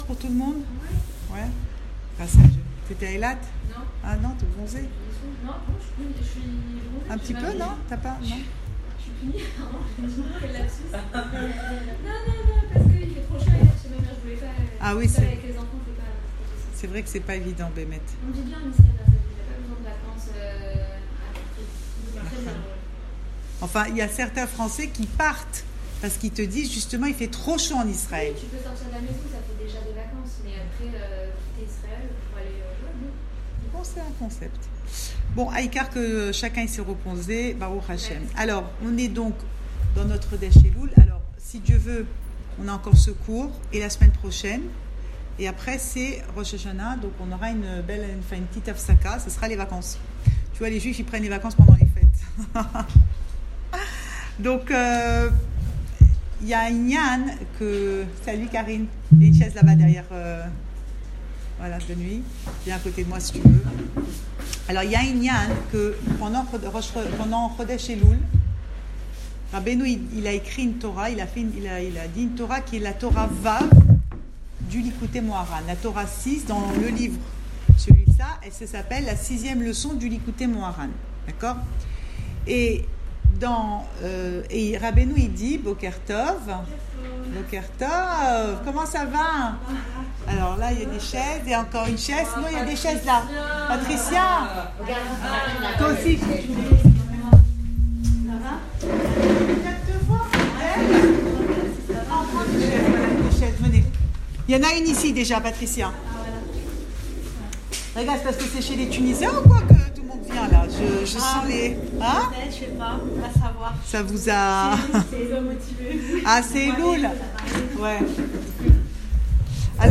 Cours audio
Enregistré à Raanana